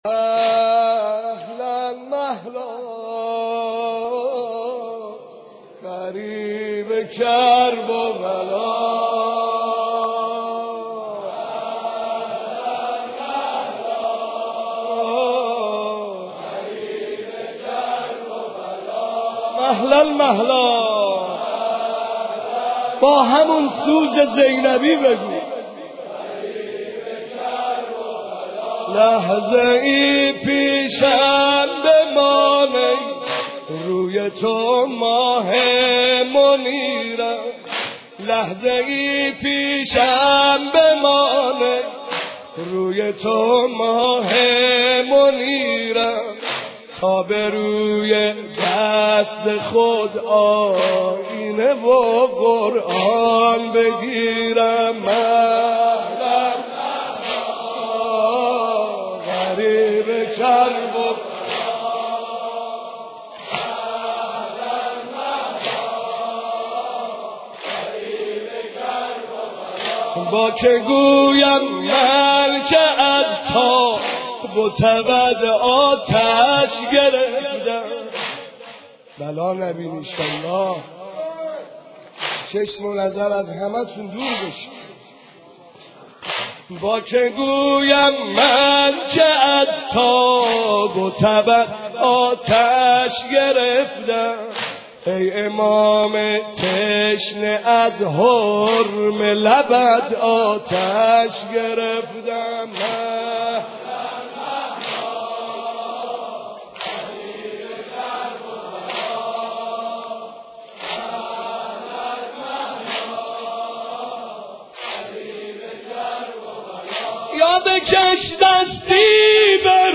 دانلود سینه زنی